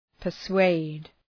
Προφορά
{pər’sweıd}
persuade.mp3